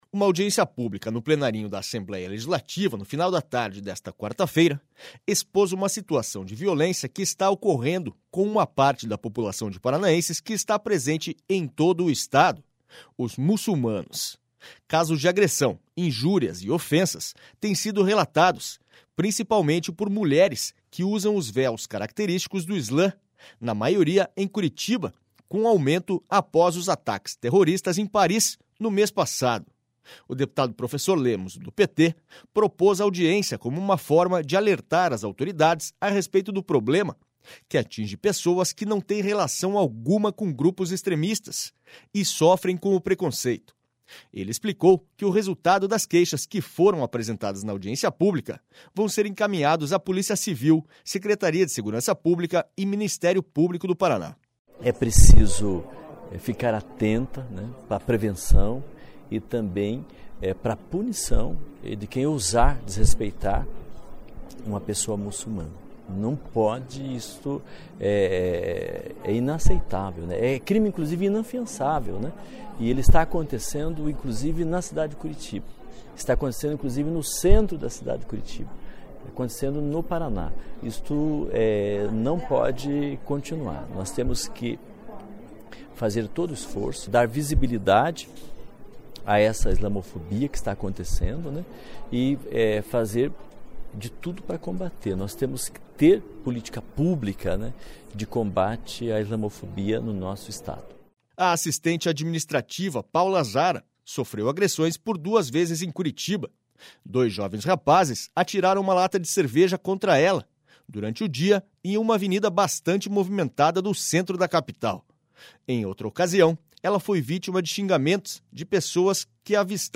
Audiência pública na Assembleia expõe islamofobia no Paraná